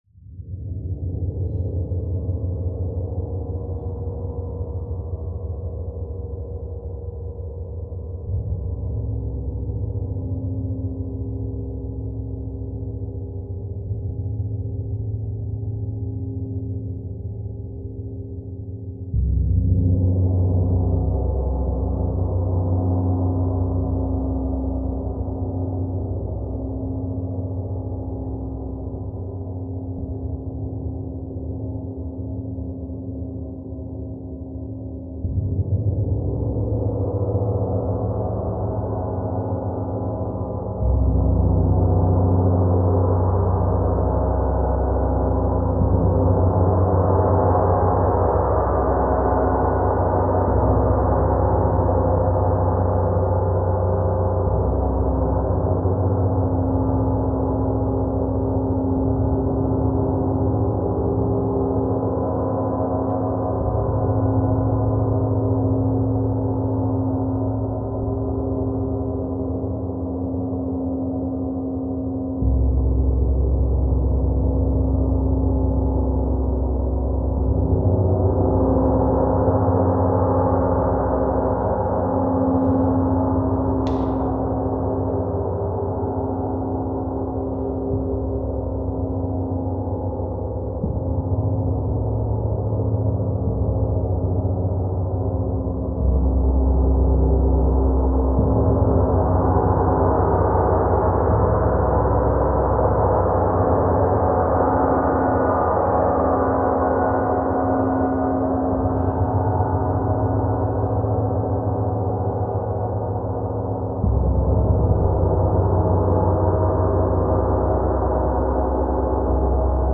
Esta grabación es la real del Gong disponible
Gong Mercurio 60cm